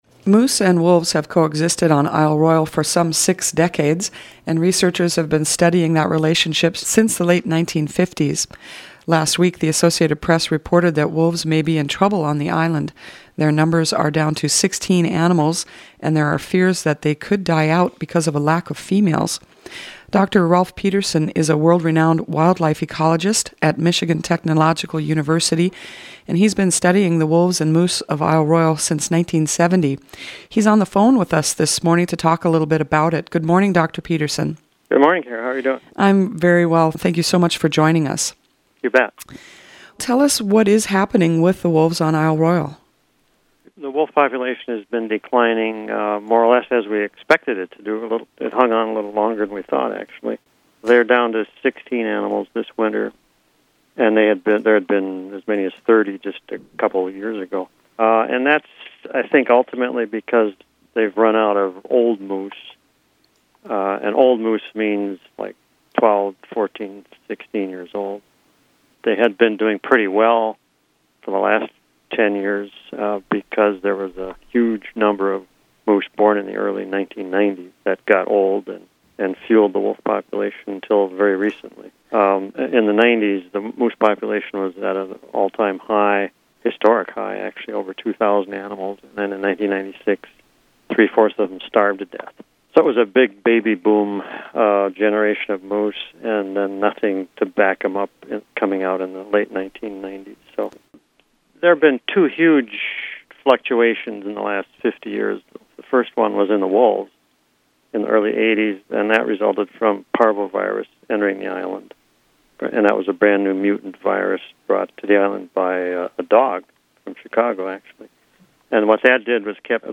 He spoke with WTIP reporter